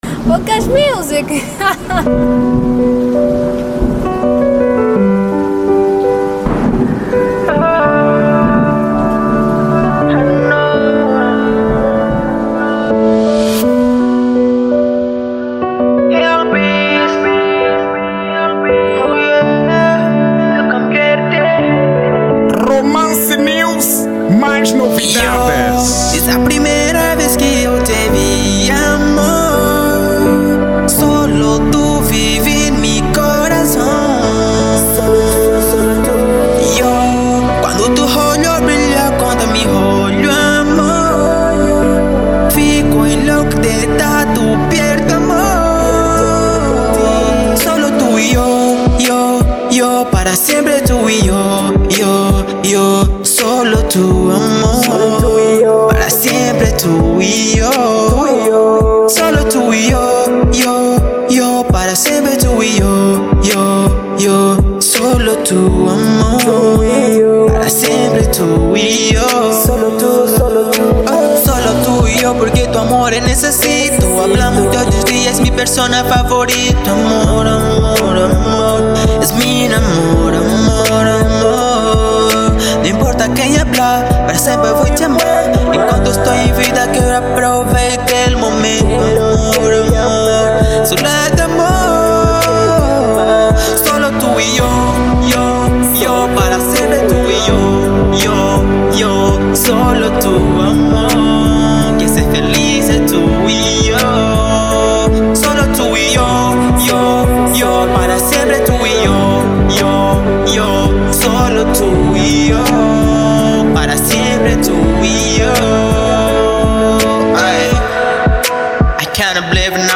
Estilo: Reggeatton